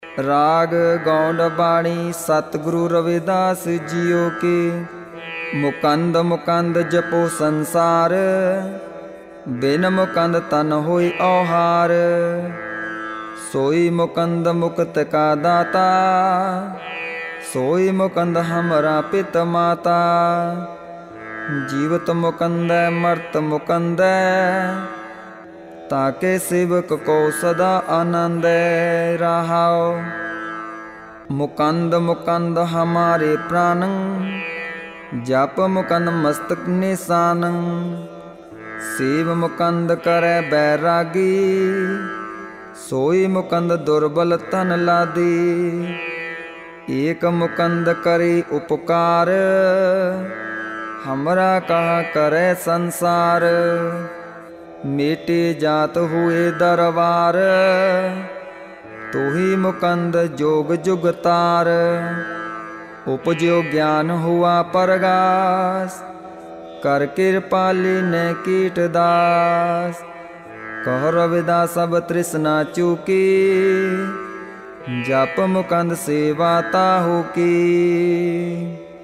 ਰਾਗੁ ਗੋਂਡ ਬਾਣੀ ਰਵਿਦਾਸ ਜੀਉ ਕੀ